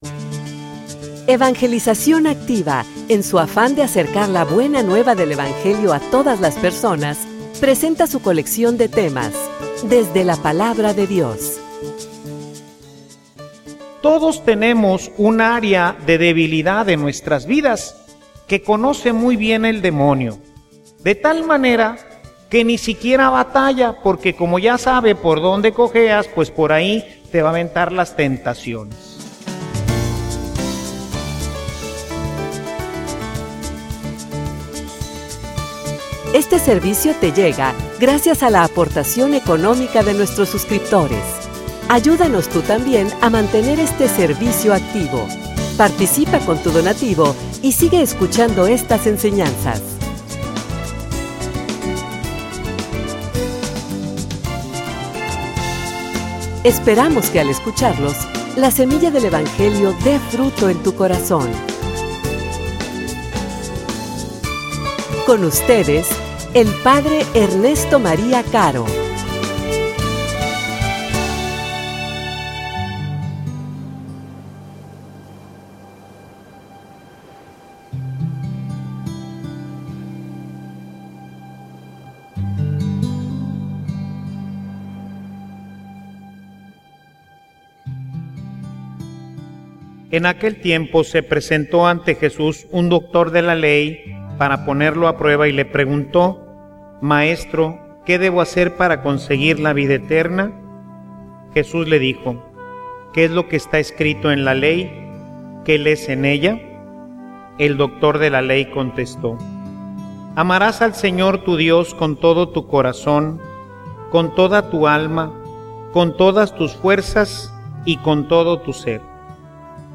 homilia_No_pierdas_la_esperanza.mp3